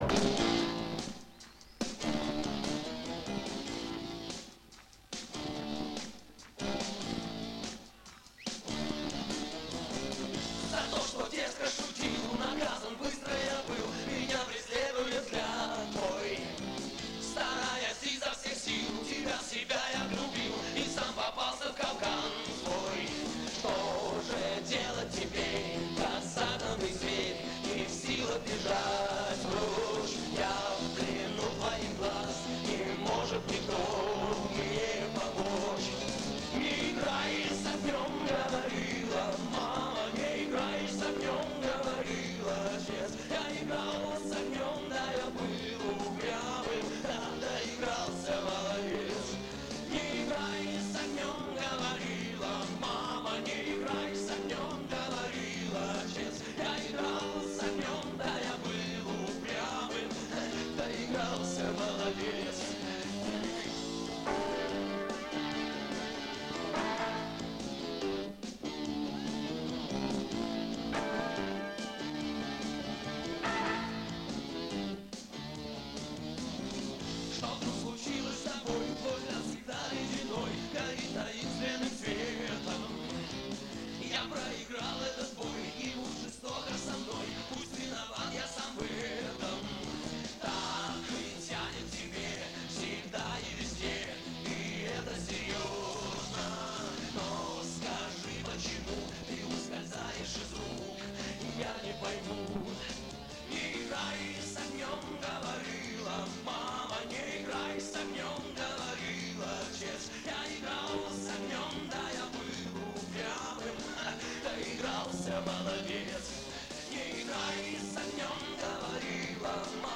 Качество плохое,но это лучше,чем вообще отсутствие песни.